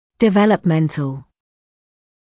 [dɪˌveləp’mentl][диˌвэлэп’мэнтл]связанный с развитием